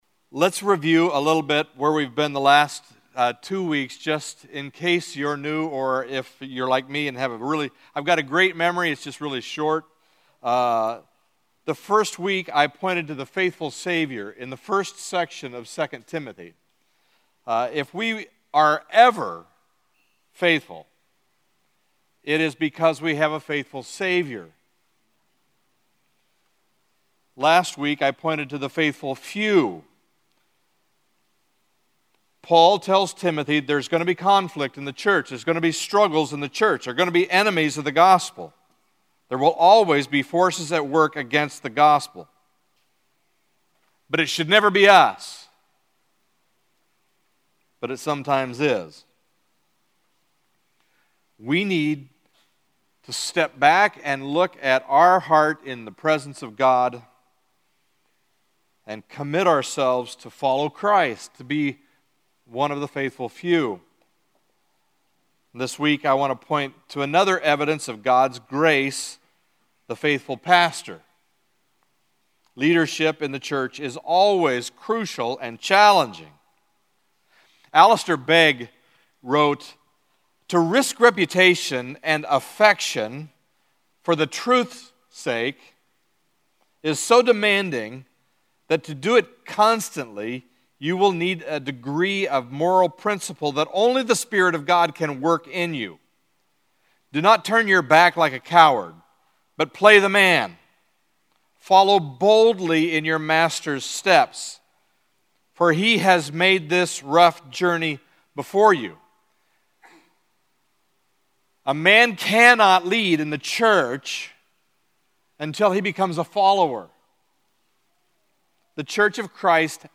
Guest Speaker Church Leadership